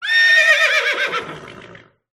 Odgłosy zwierząt wiejskich
Koń
audio_hero_s-horse-whinny.mp3